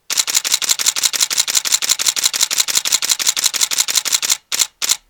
Canon 5D Mk III sorozat hangja (MP3)
canon_sorozat.mp3